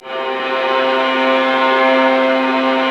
Index of /90_sSampleCDs/Roland LCDP13 String Sections/STR_Violas FX/STR_Vas Sul Pont